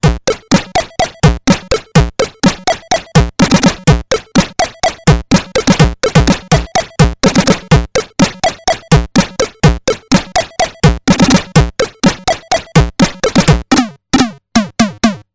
Playing wavetable drums
In this sound demo I've used the ripped drum sounds of Rob Hubbard's "Auf Wiedersehen Monty".